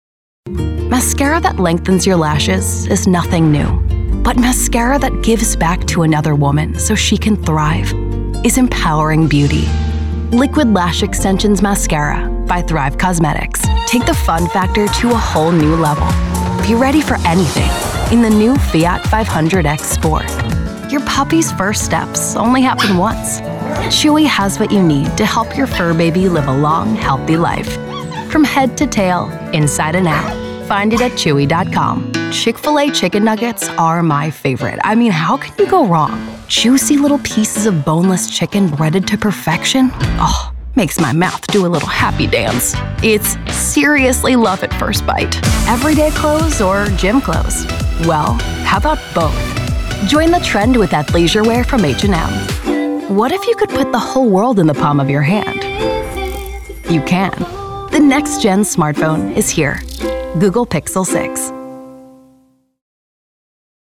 Warm, Friendly, Conversational.
Commercial